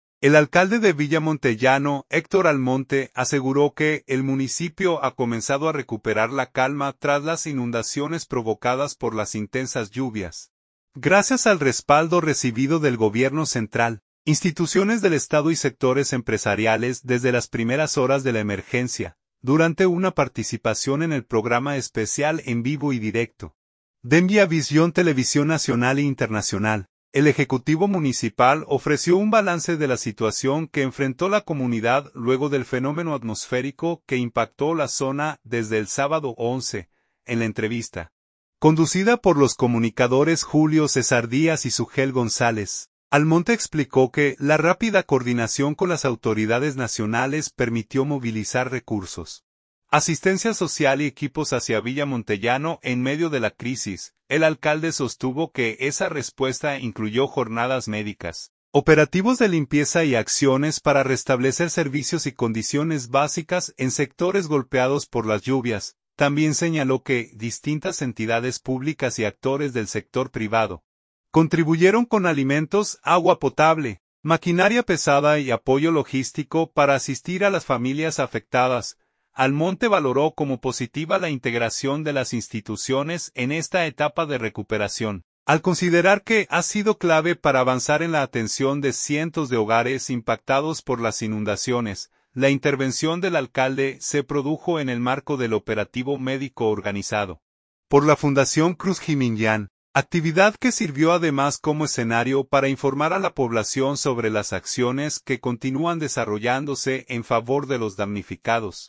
Durante una participación en el programa especial “En Vivo y Directo”, de Miavisión Televisión Nacional e Internacional, el ejecutivo municipal ofreció un balance de la situación que enfrentó la comunidad luego del fenómeno atmosférico que impactó la zona desde el sábado 11.